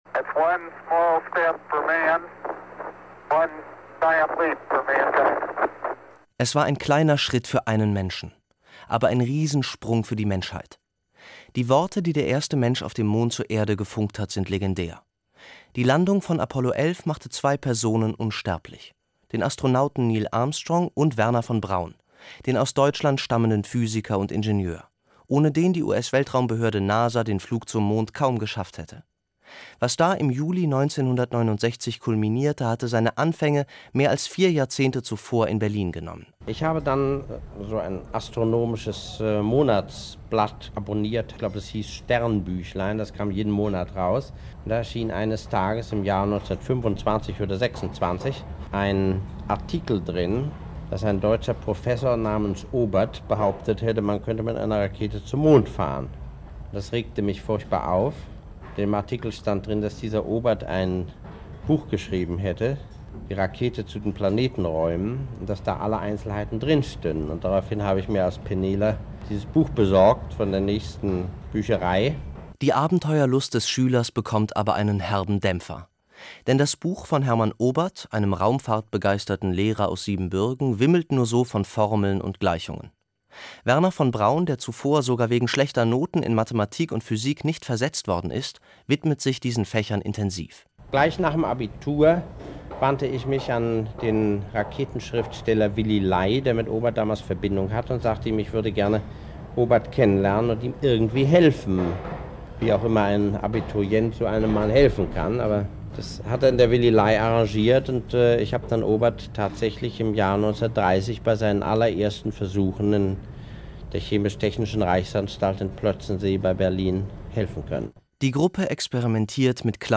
Wernher von Braun. Ein Porträt zu seinem 100. Geburtstag.